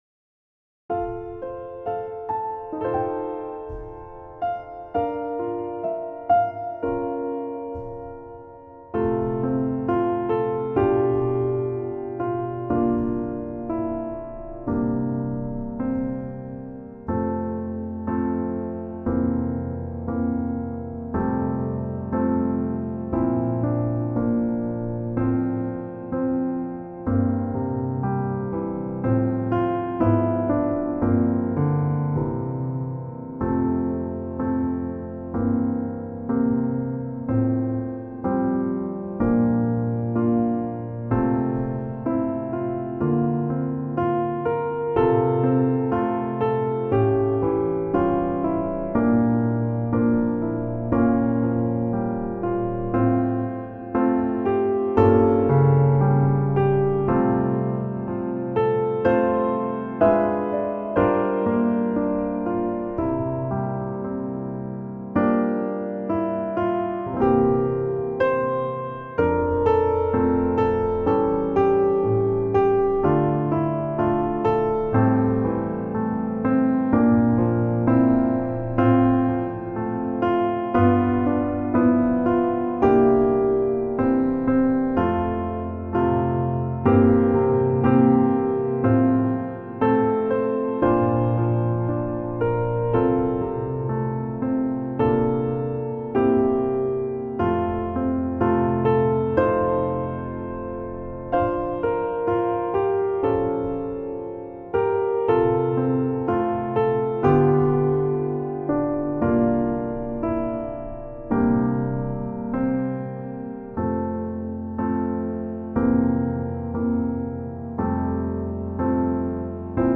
Choir Unison, Vocal Solo, Youth Choir Mixed Or Unison